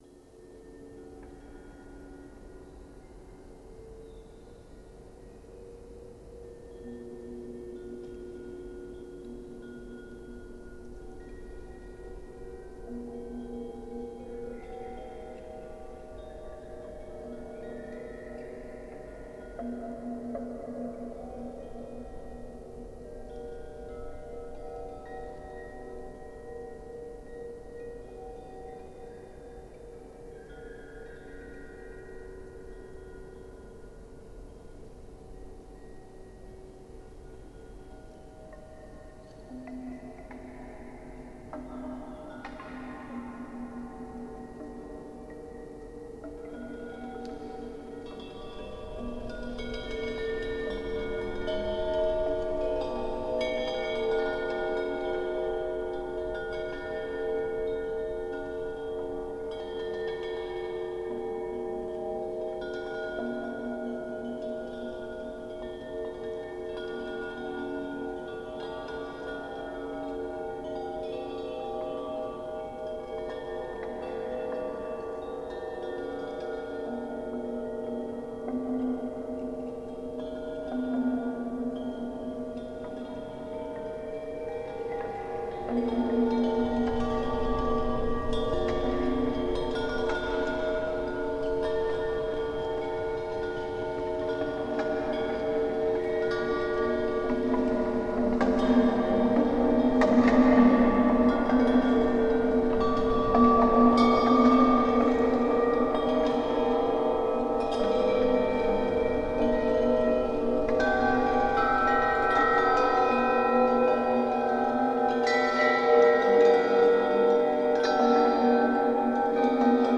Transformed wind chimes: Nature-Symphony 36 Sound Effect — Free Download | Funny Sound Effects
Nature-Symphony 36 (Surreal landscape of contradictory impressions!)